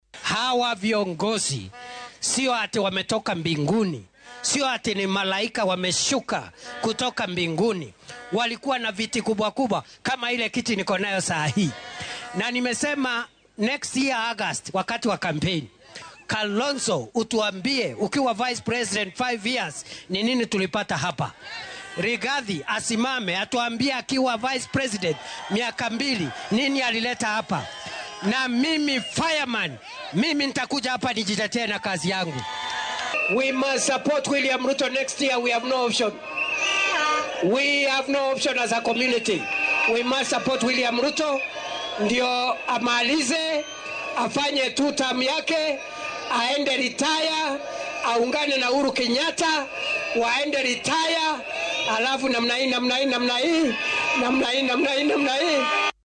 Isagoo ku sugnaa ismaamulka Meru halkaas oo uu ku kormeerayay mashaariic horumarineed, ayuu Kindiki sheegay inuu kalsooni buuxda ku qabo in Madaxweyne William Ruto uu si fudud uga adkaan doono mucaaradka doorashada soo socota, sababo la xiriira fulinta ballanqaadyada dowladdiisa.